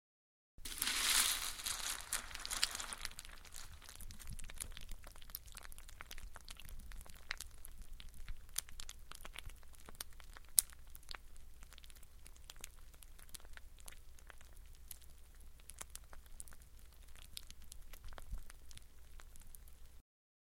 • Качество: высокое
Звук просеивания крупы